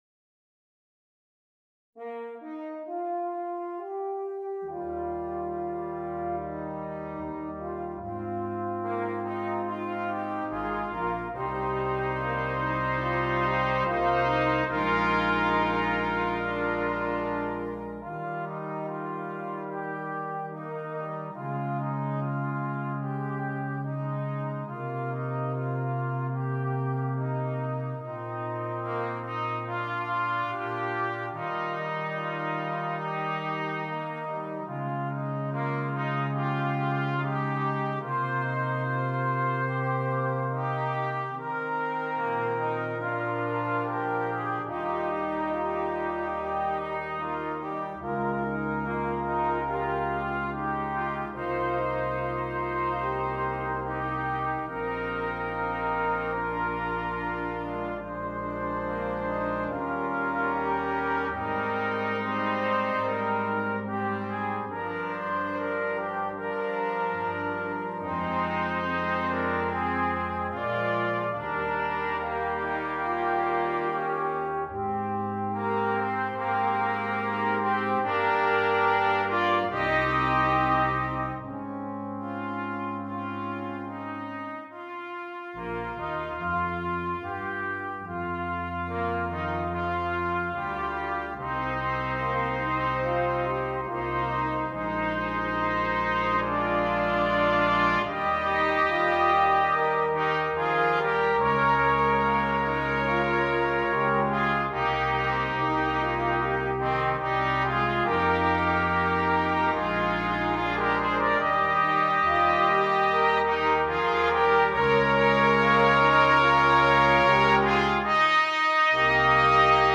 Brass Quintet
simple lyrical composition in the style of a lullaby